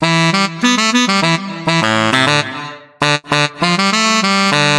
廉价萨克斯的RnB
描述：这是一个2小节（2x4/4）的萨克斯音符循环。第一小节是E调。第二小节降为D调，因为它更容易做一个基调；)
Tag: 100 bpm RnB Loops Brass Loops 827.00 KB wav Key : E